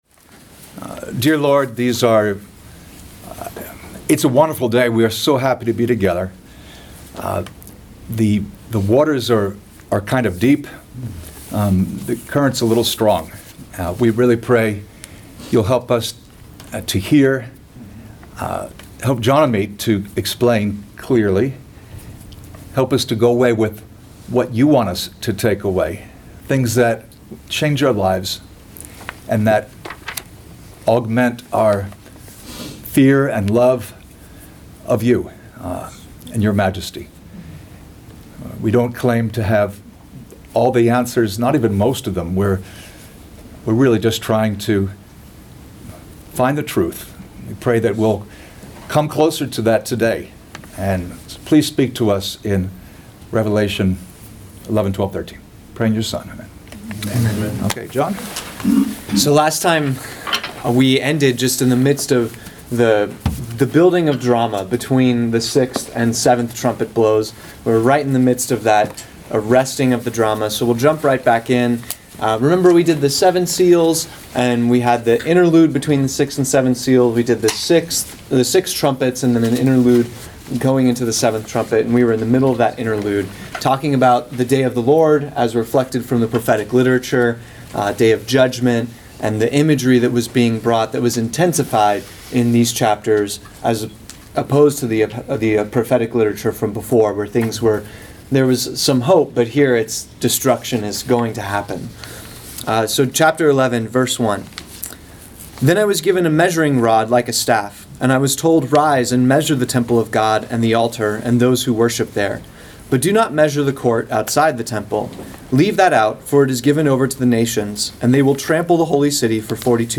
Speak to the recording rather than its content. The fourth of 7 classes on the Book of Revelation, taught live at the North River Church of Christ at 9 am on the dates below.